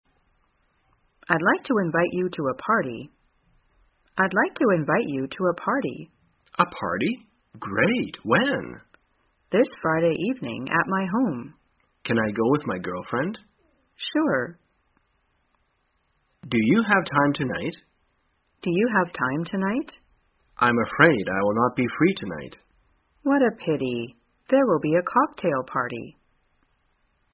在线英语听力室生活口语天天说 第275期:怎样邀请别人的听力文件下载,《生活口语天天说》栏目将日常生活中最常用到的口语句型进行收集和重点讲解。真人发音配字幕帮助英语爱好者们练习听力并进行口语跟读。